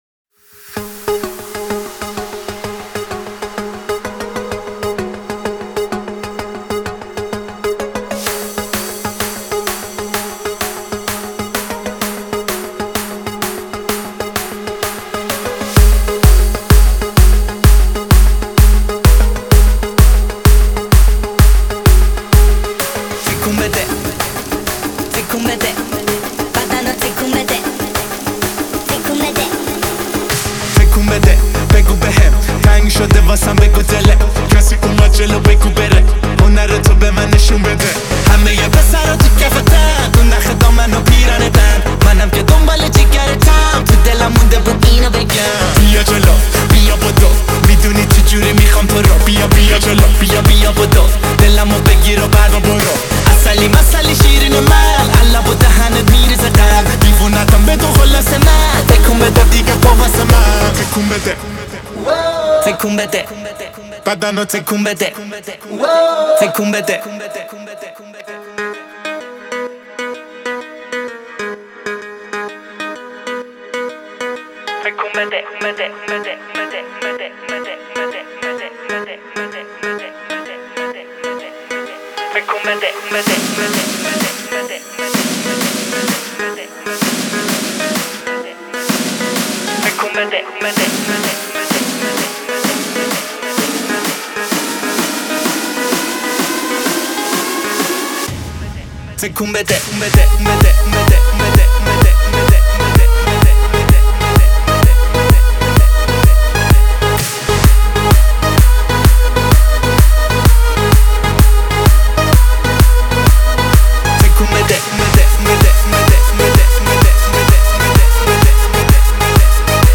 کلاب ریمیکس